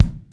R.L KICK4.wav